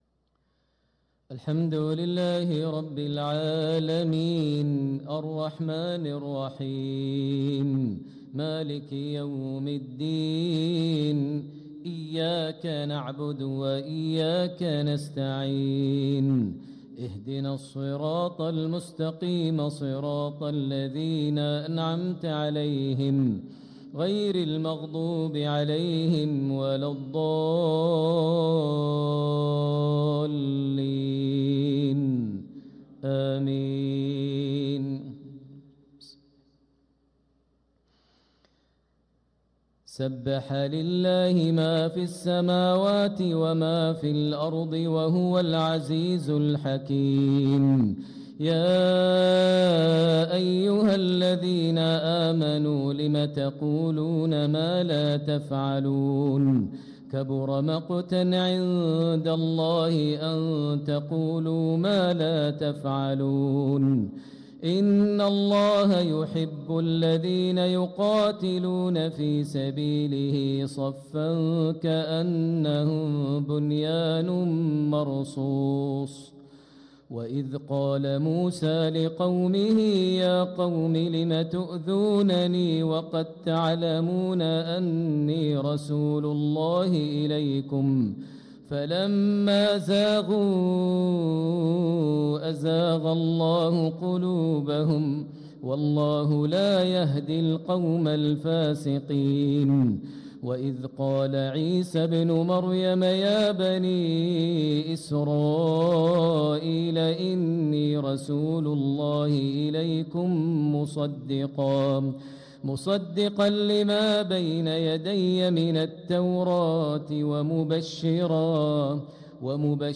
صلاة العشاء للقارئ عبدالرحمن السديس 19 صفر 1446 هـ
تِلَاوَات الْحَرَمَيْن .